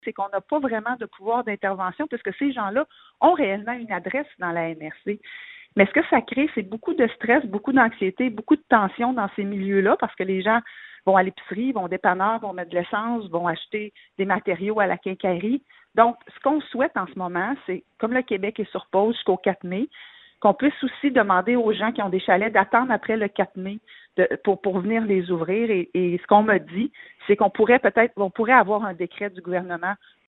Écouter la préfète de la MRC de Nicolet-Yamaska, Geneviève Dubois :